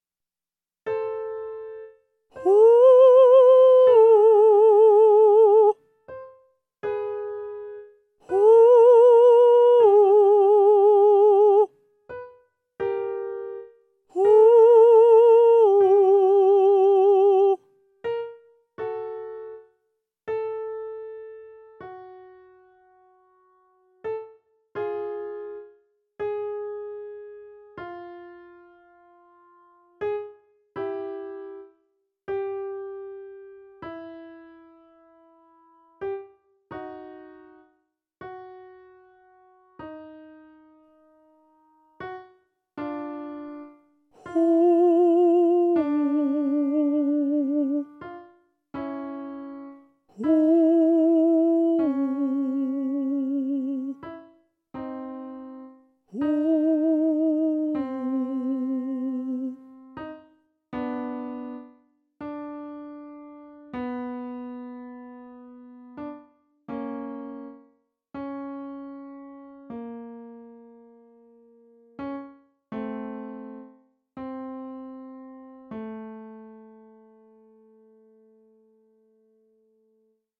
裏声／ヘッドボイスの練習方法
音量注意！
1. 発音は「ホ」
• 「オ」母音はなるべく暗く「ア」に寄らないように発音しましょう
2. 音域はA3～C5
• スタートはC5から半音ずつ下降していきます
3. 音量は徐々に小さくなる
practice-falsetto-headvoice-02.mp3